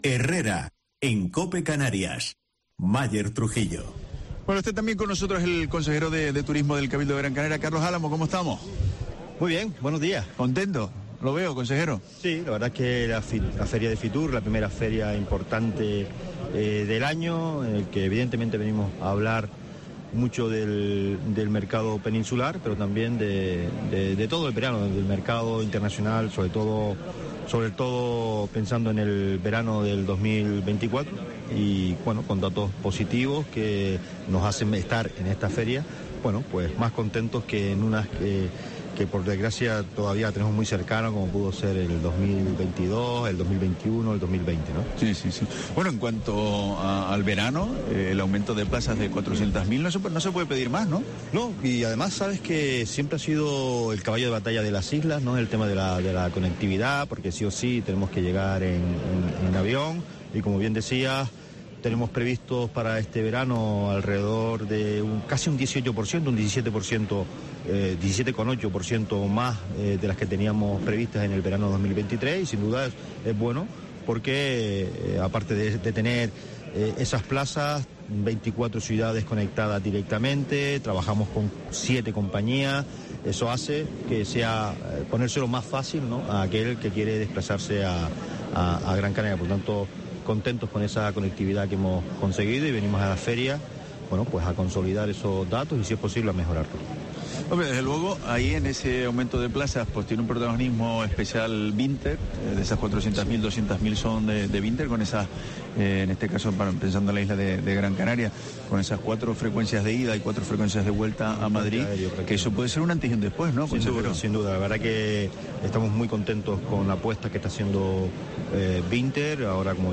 Carlos Álamo, consejero de Turismo del Cabildo de Gran Canaria
Por Herrera en COPE Canarias, pasó Carlos Álamo, consejero de Turismo, quien destaca la necesidad de una campaña específica para atraer al cliente nacional en verano, con el objetivo de ocupar esas plazas con turistas españoles, además destaca el impacto del propio turista regional "Gran Canaria es la isla que mayor número de turistas de las islas recibe y no sentimos satisfechos de ponérselo fácil a aquellos turistas que nos eligen para desplazarse entre islas".